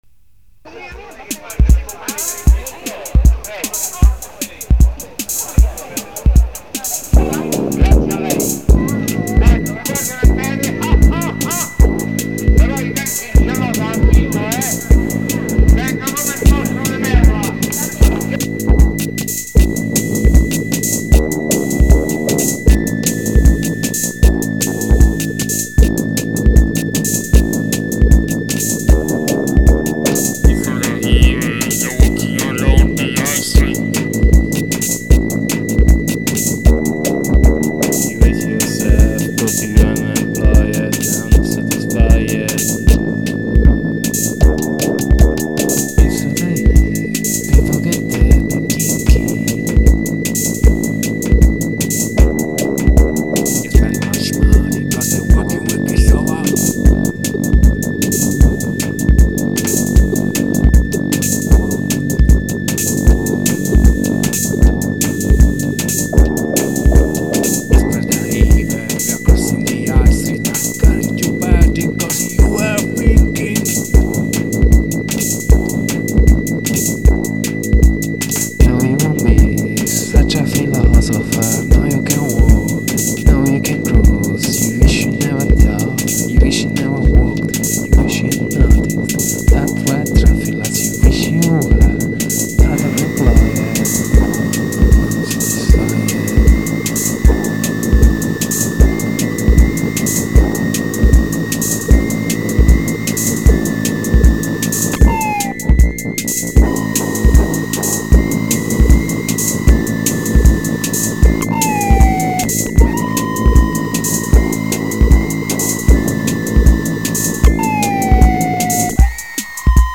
voce, basso, organo;
batteria.
Lo-tecnopop come solo a Roma poteva essere.